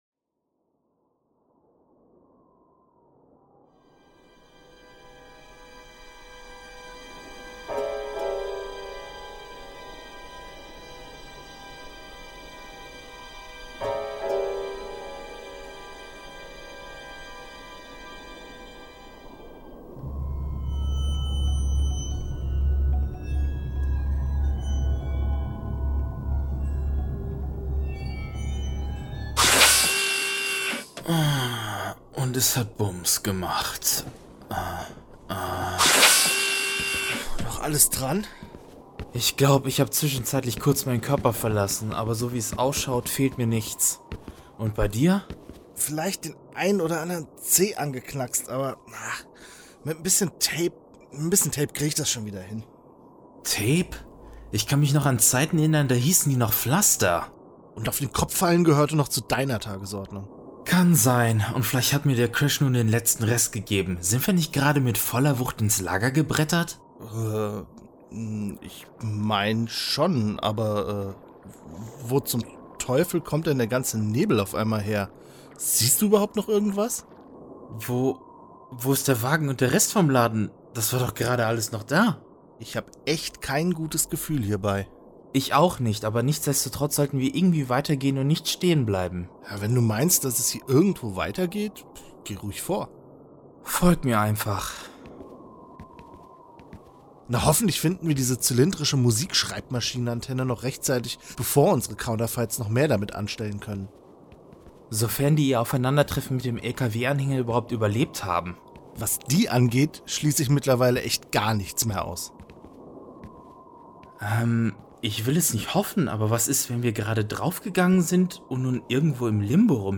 Wir hoffen, dass ihr alle heute Morgen was Nettes in euren Stiefeln, Schuhen oder Strümpfen gefunden habt und jetzt noch Platz für einen kleinen Nachschlag habt, denn mit ein wenig Verspätung melden wir uns endlich mit der dritten Folge unserer Hörspiel-Fortsetzung "Back In Time II" zurück, die es echt in sich hat.